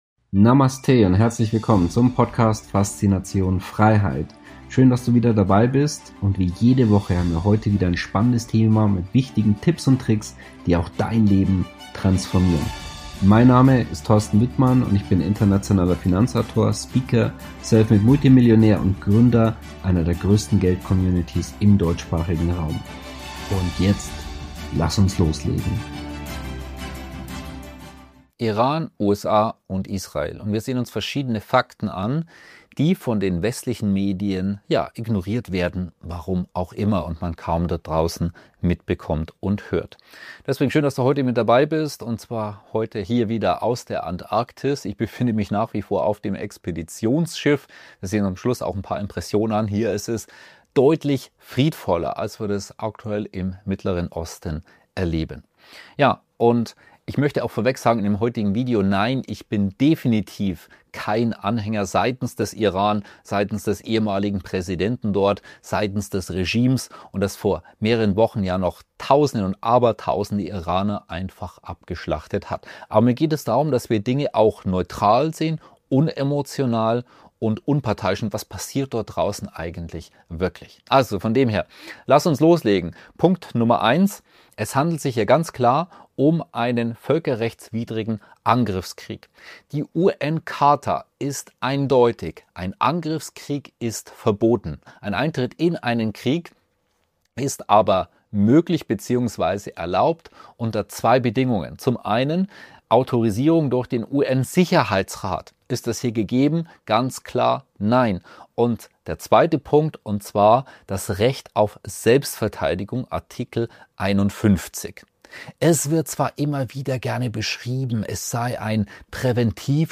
Beschreibung vor 1 Monat Iran, USA, Israel – 5 Fakten, die die Medien verschweigen Ich sitze gerade in der Antarktis.